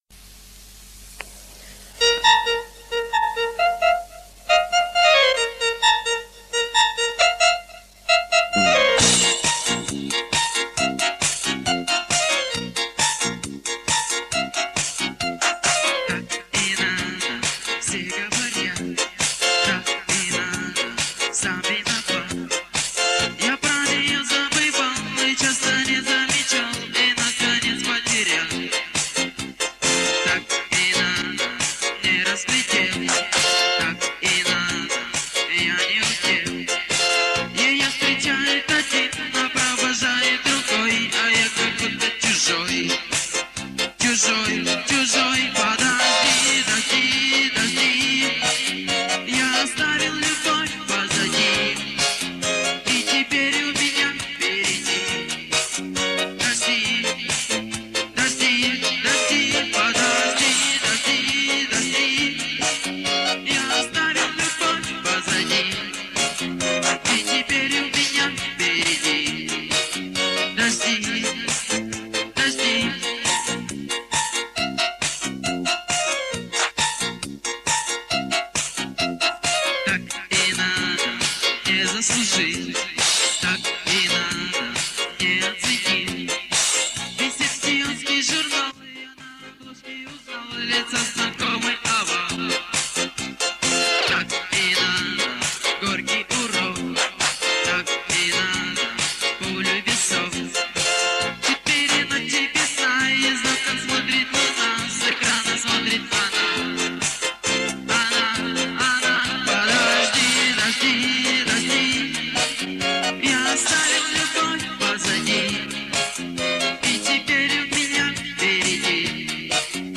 Вокал